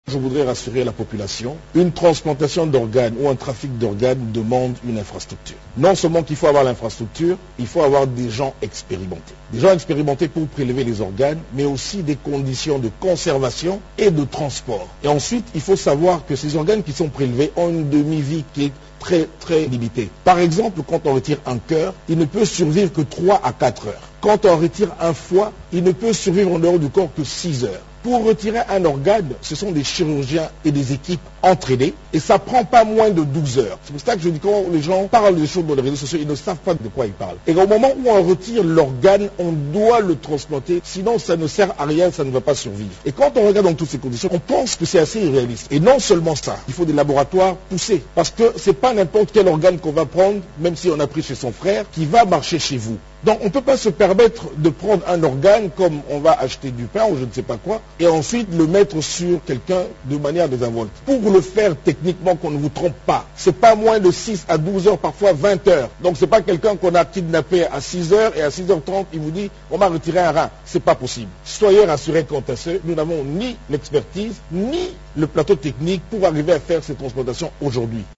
Il l’a dit au cours d’une conférence de presse conjointement animée avec le ministre de la Communication et le vice-ministre de la Santé à Kinshasa.